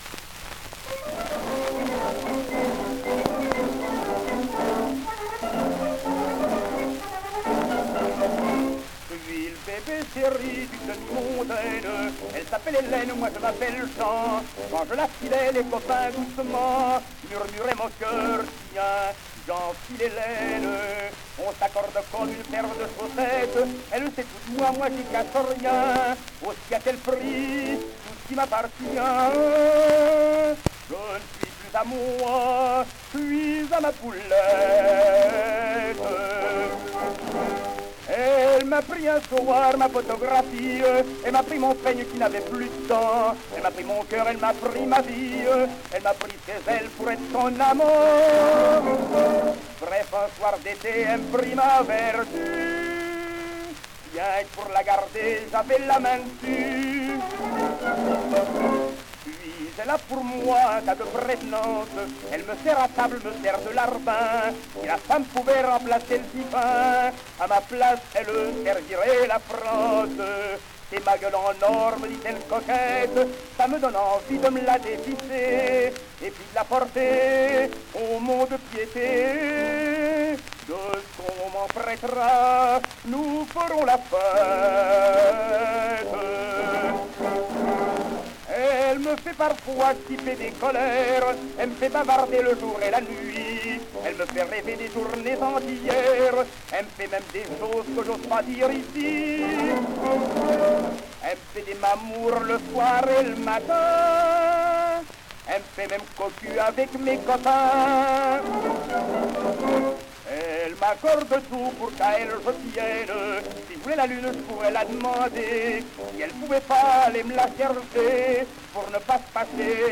Caf' Conc